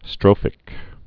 (strōfĭk, strŏfĭk)